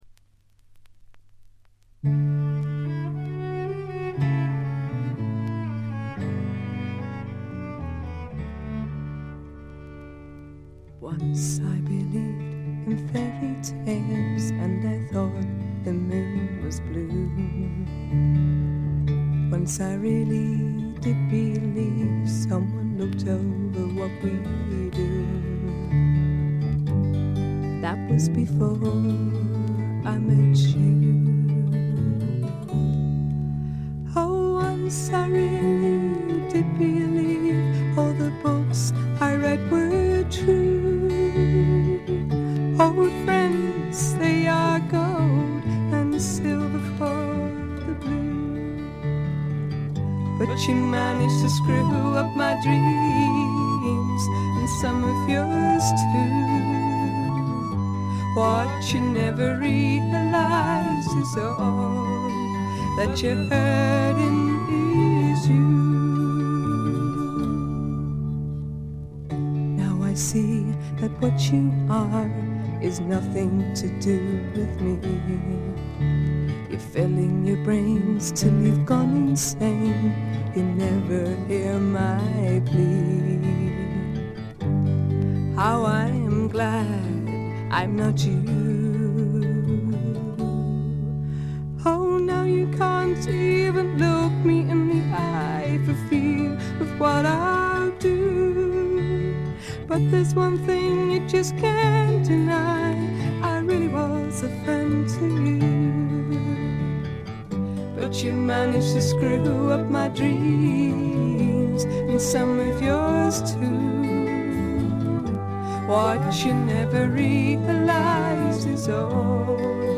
軽微なバックグラウンドノイズ。
試聴曲は現品からの取り込み音源です。